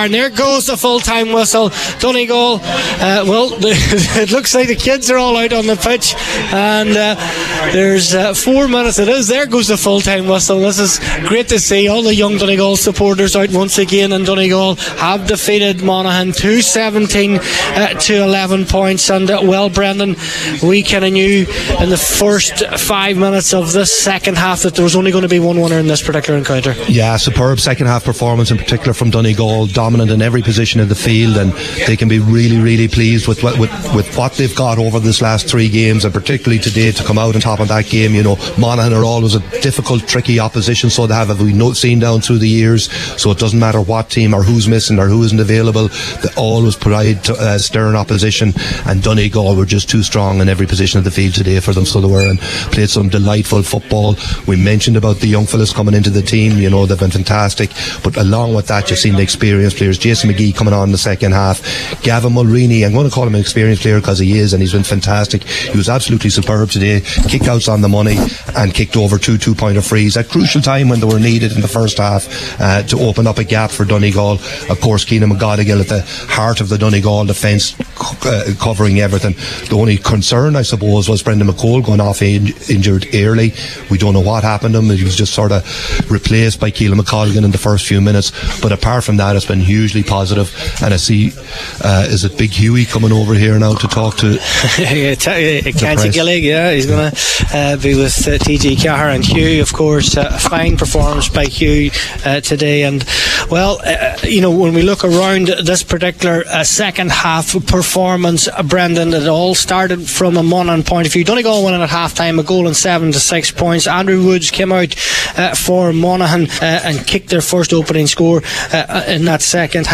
Post-Match Reaction